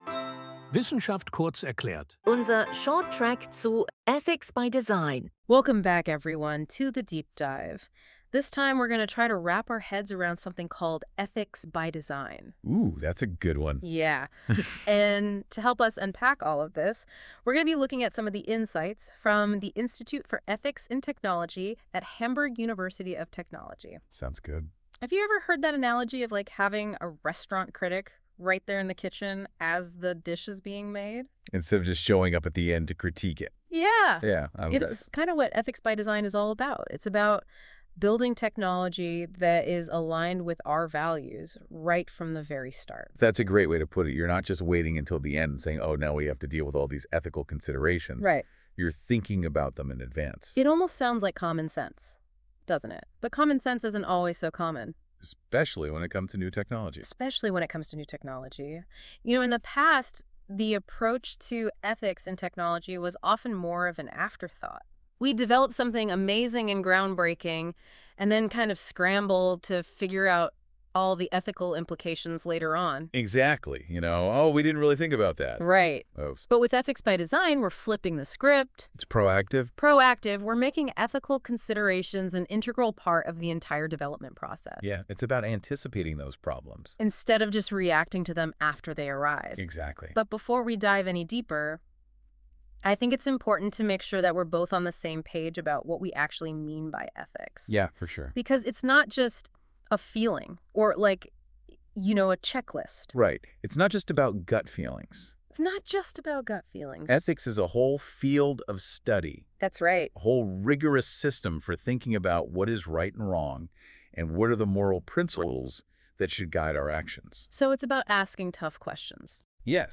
In this exciting, bite-sized podcast, the core concepts of Ethics by Design are broken down in a way that's easy to understand and thought-provoking. AI-generated with Notebook LM and carefully edited, it's the perfect listen to understand the future of ethical tech in just a few minutes!